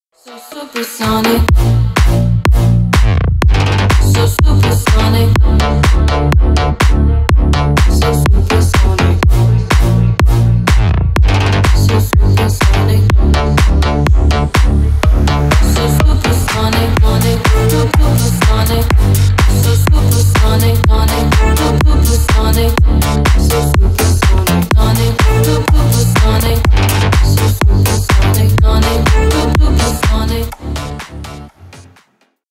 • Качество: 320 kbps, Stereo
Танцевальные
клубные